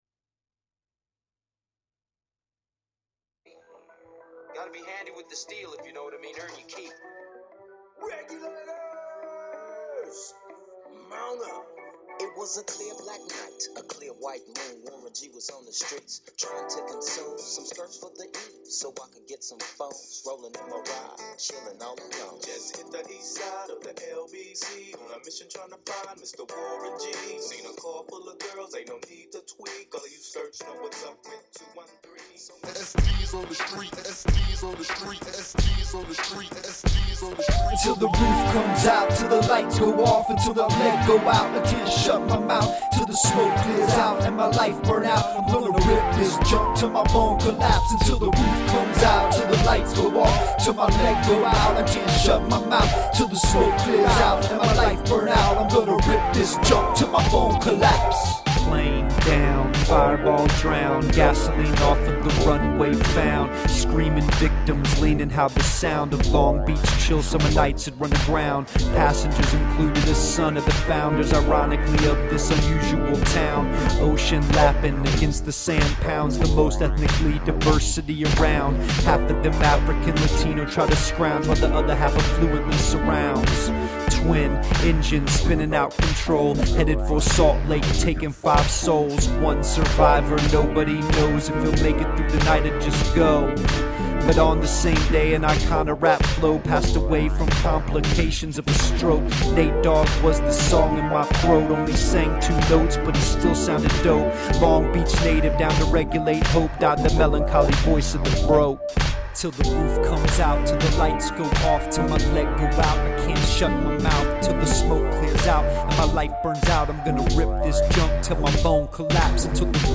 Lyrics include classic Nate Dogg material.